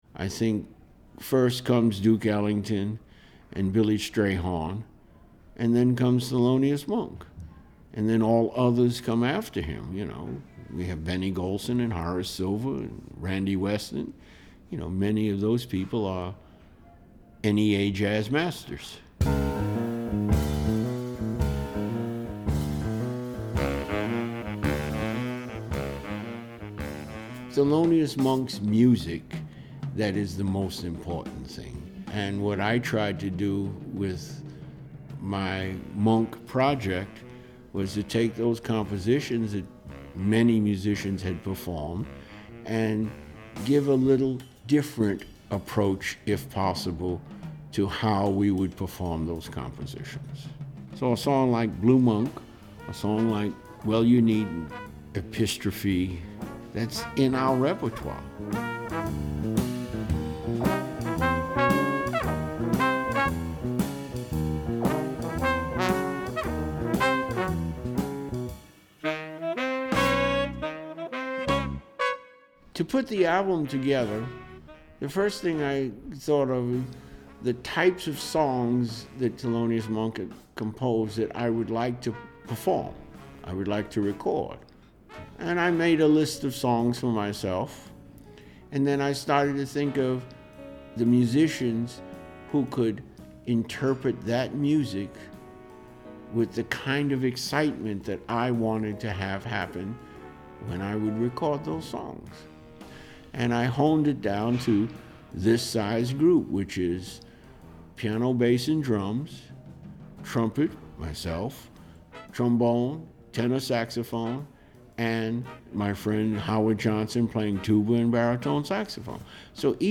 2012 NEA Jazz Master Jimmy Owens shares his thoughts on his latest CD, "The Monk Project." [2:57]